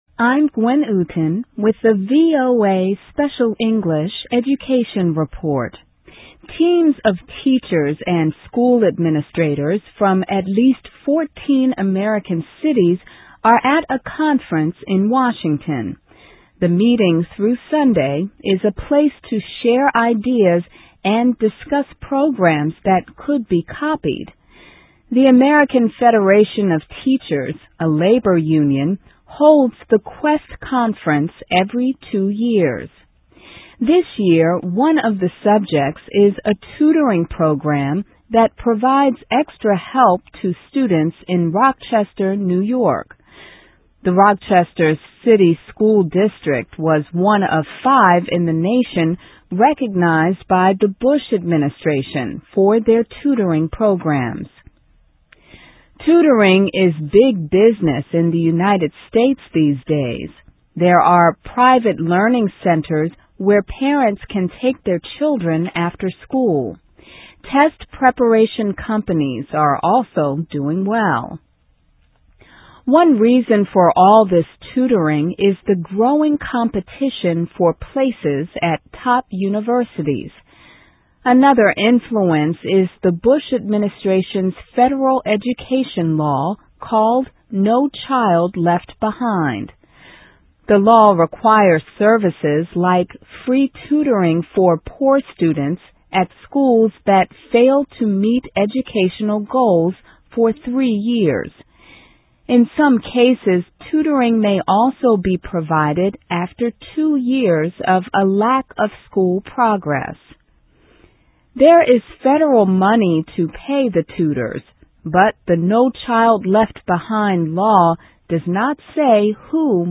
se-education-tutor.mp3